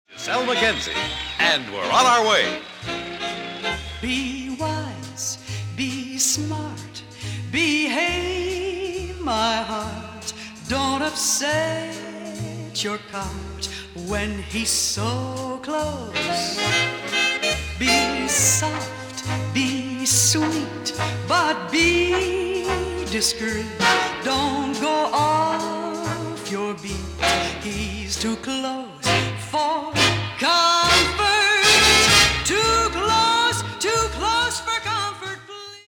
female vocalists
Canadian popular and jazz music